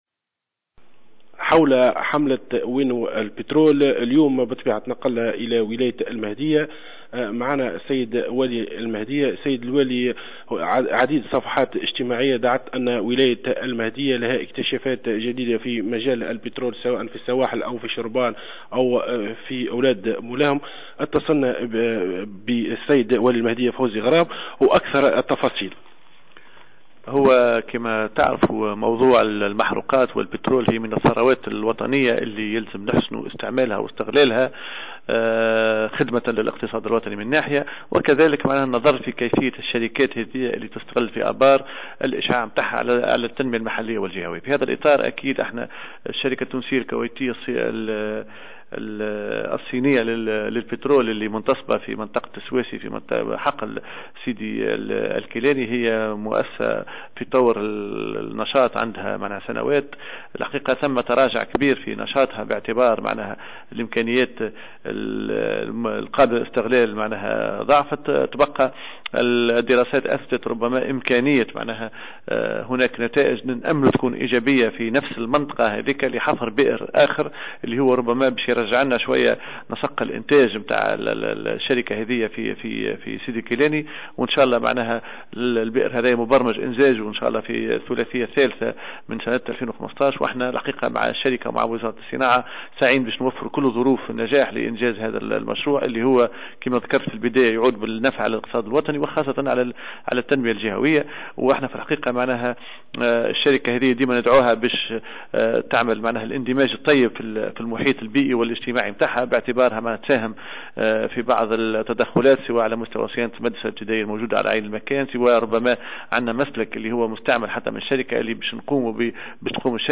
واعتبر الوالي، في تصريح للجوهرة أف أم اليوم الاربعاء، أن هذا الاكتشاف سيعود بالنفع على الاقتصاد الوطني سيما وأن إنتاج البئر الأولى في هذه المنطقة قد سجل مؤخرا تراجعا ملحوظا.